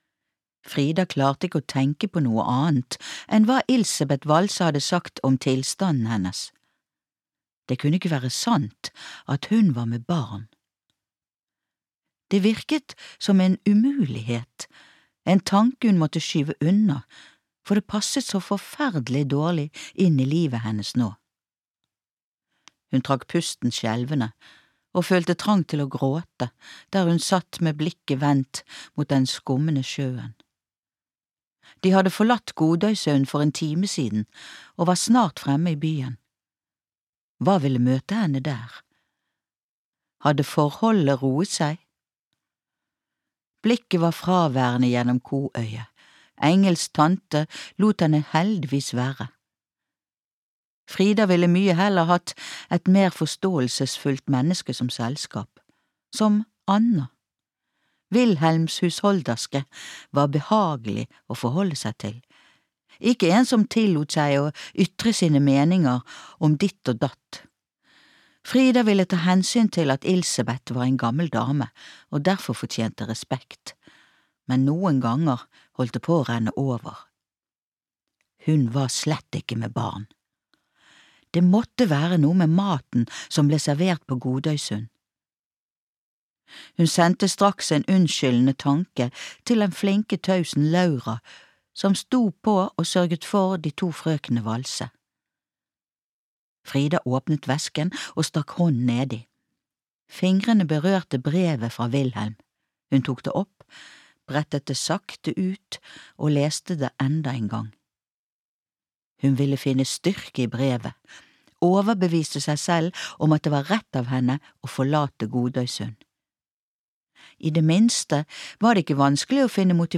Utdraget er hentet fra lydbokbokutgaven